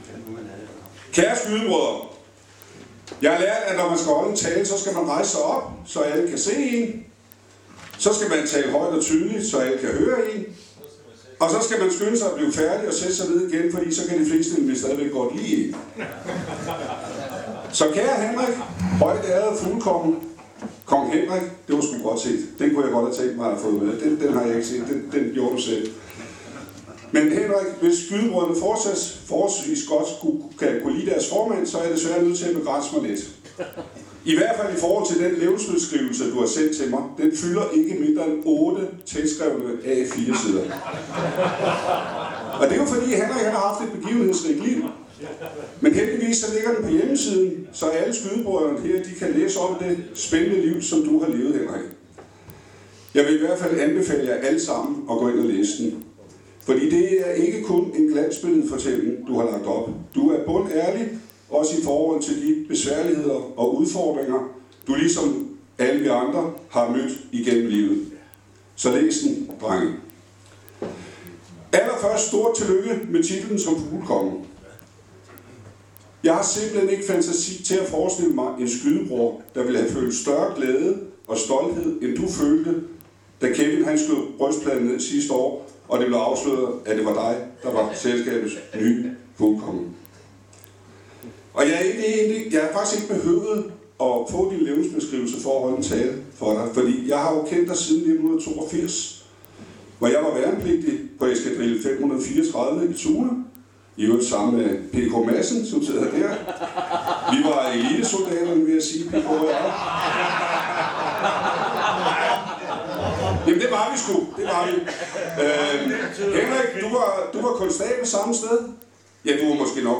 Under årets fugleskydningsfrokost afholdes fem taler.
Herefter taler selskabets formand for fuglekongen.
Formandens tale til fuglekongen 2024.mp3